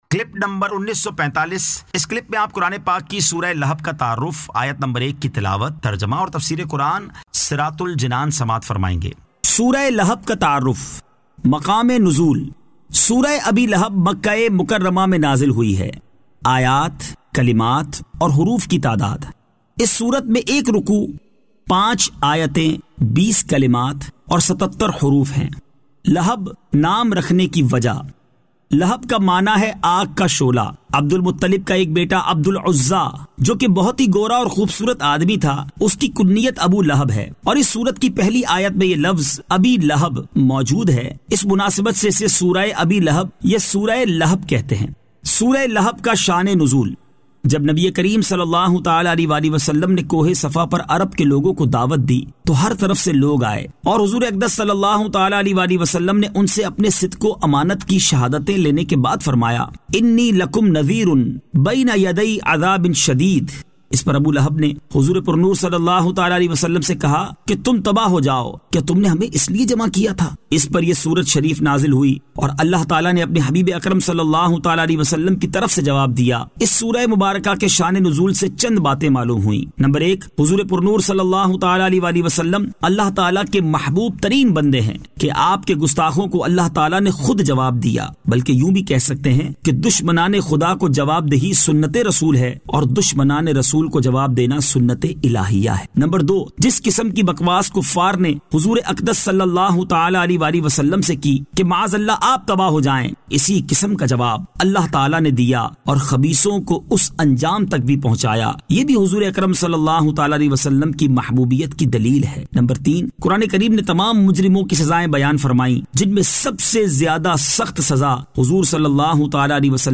Surah Al- Lahab 01 To 01 Tilawat , Tarjama , Tafseer